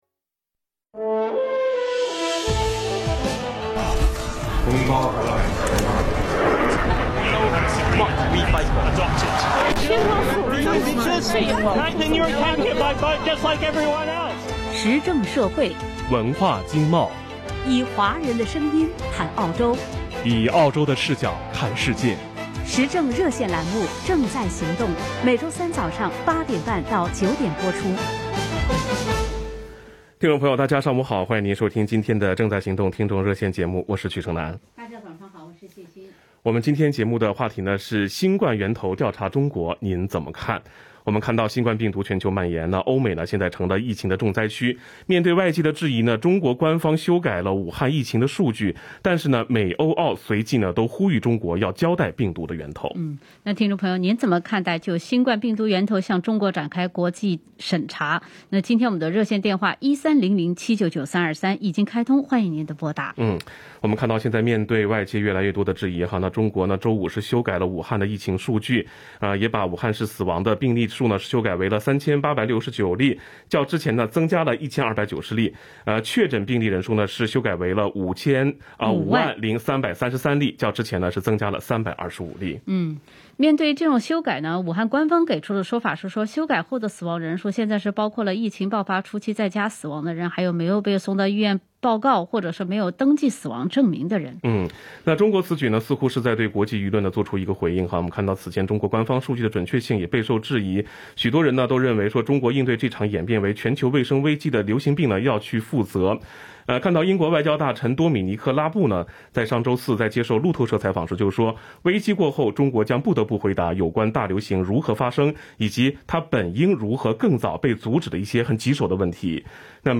【Covid-19 报道】新冠源头审查中国，您怎么看 - 正在行动听众热线